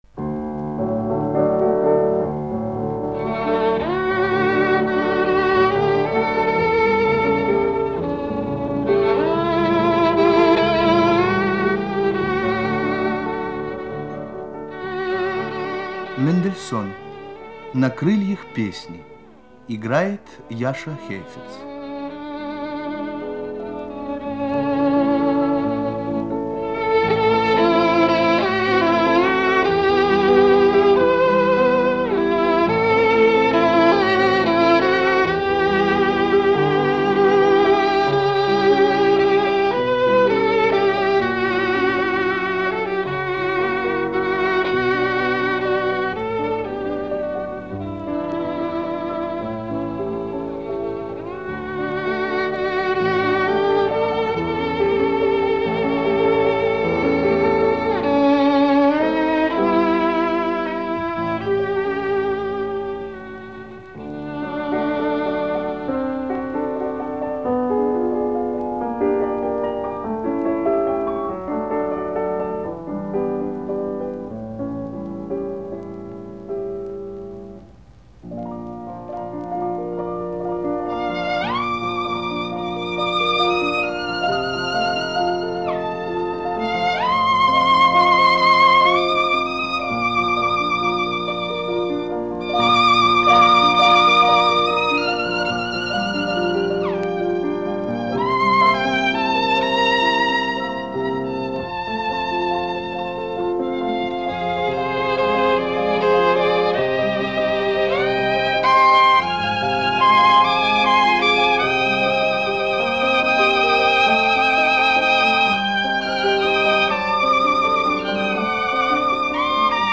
Звуковая страница 6 - Знаменитые скрипки мира. Играют Я.Хейфец (США) и Л.Коган (СССР).